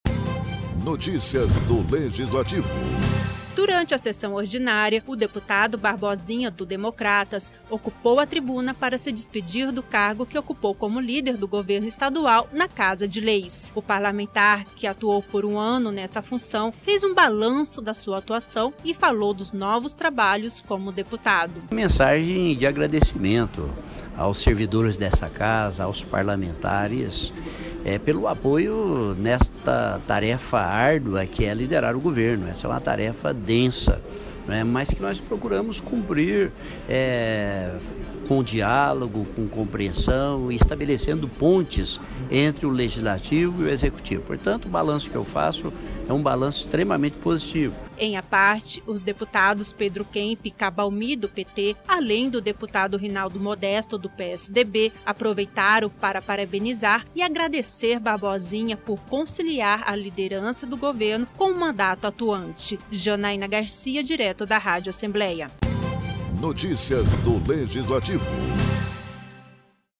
Na sessão ordinária na Assembleia Legislativa, o deputado Barbosinha (DEM), ocupou a tribuna para se despedir da liderança do governo estadual na Casa de leis. O parlamentar fez um balanço da sua atuação.